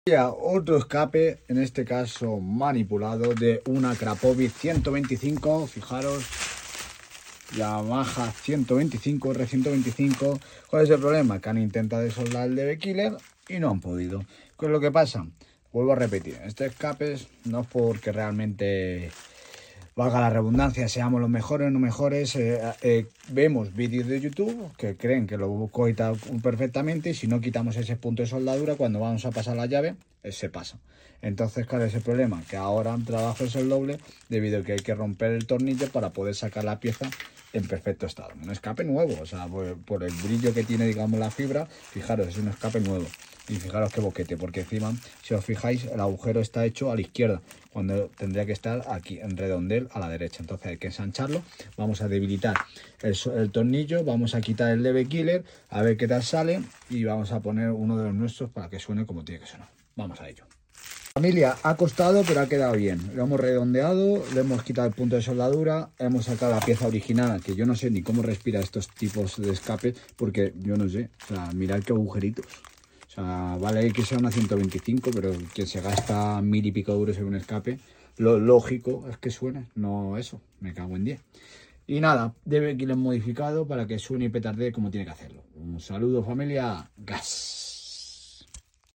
¿El resultado? 🔊 Más sonido 💥 Más petardeo 😎 Y una R125 que ahora suena como toda una campeona.
🛠 We fixed it, removed the original, and fitted our premium modified db killer. The result? 🔊 More sound 💥 More pops & bangs 😎 And an R125 that now sounds like a real racer.